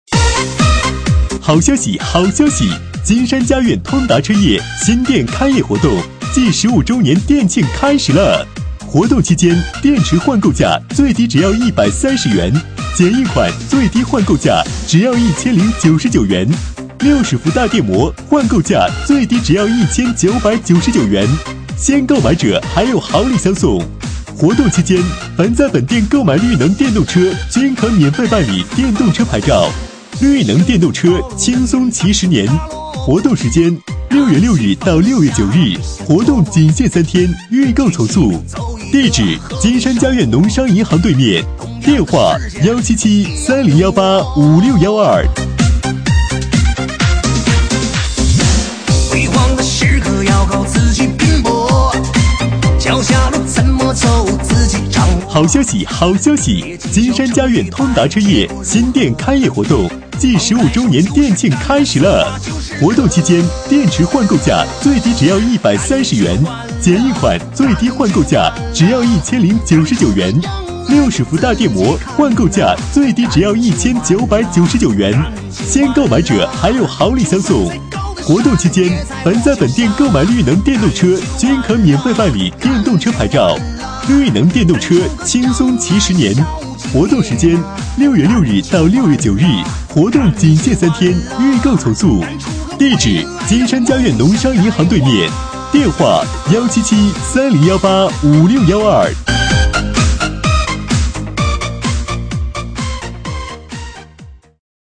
【男31号促销】金山佳苑通达车业
【男31号促销】金山佳苑通达车业.mp3